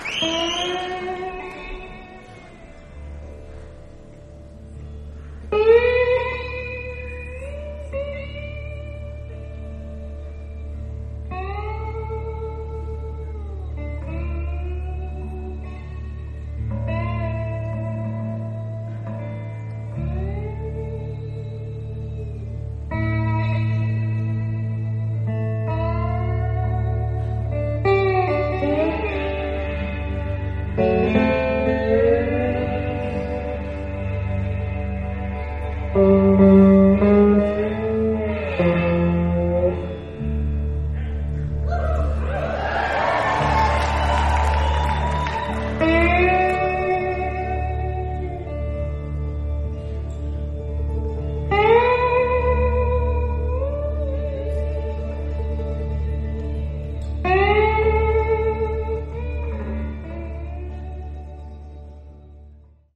Source: Beyerdynamic MC930 > Kind Kables > Fostex FR2LE
Place: Temple Hoyne Buell Theater, Denver, CO, USA